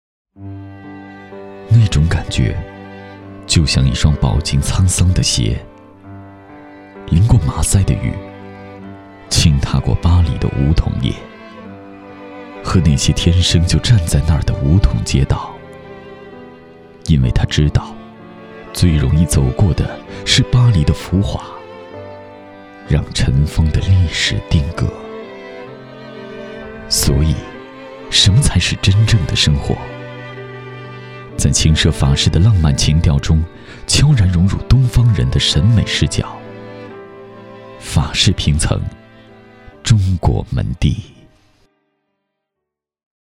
广告配音-纵声配音网
男66 商业广告—大气深情.mp3